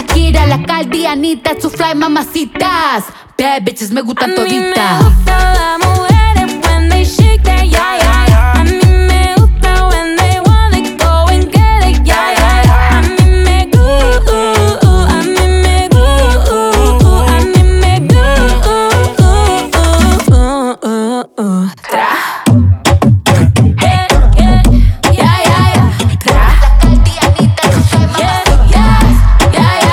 • Latino